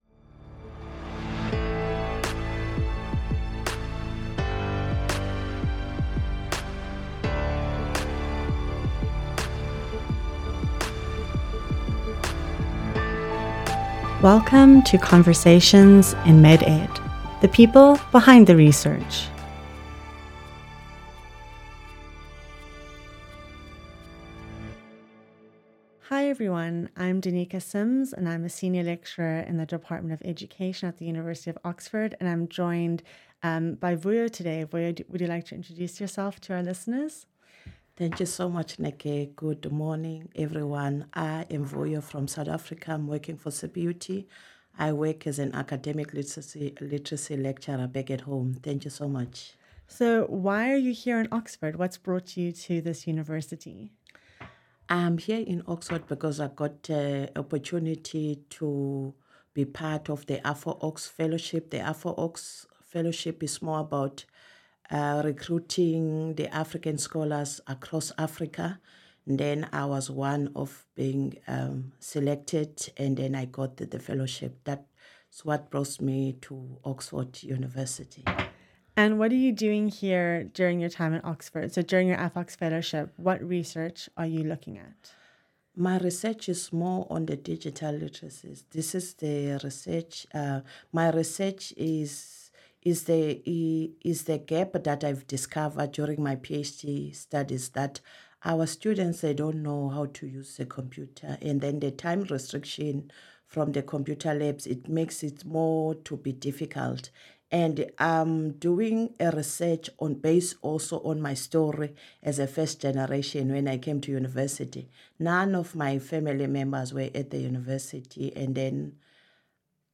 Chatting